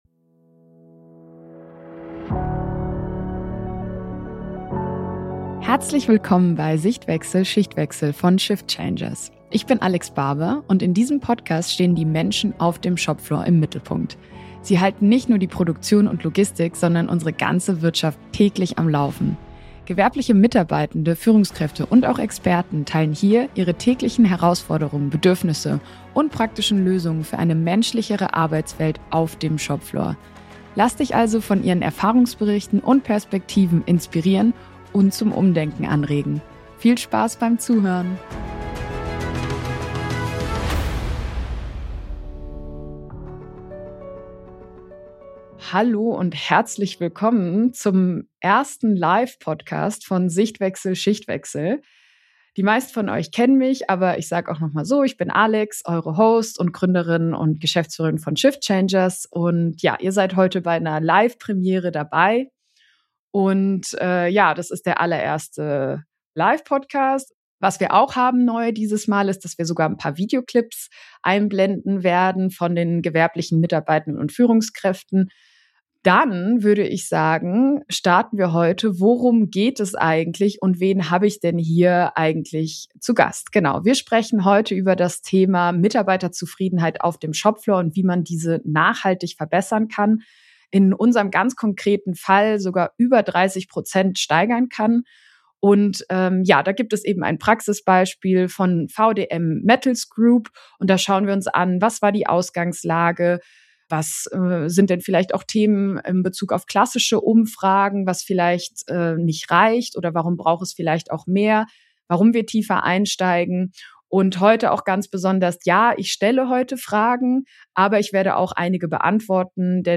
In dieser ersten Live-Podcast-Folge sprechen wir offen und praxisnah über das, was klassische Mitarbeiterbefragungen nicht zeigen und wie echte Veränderung im Alltag aussieht.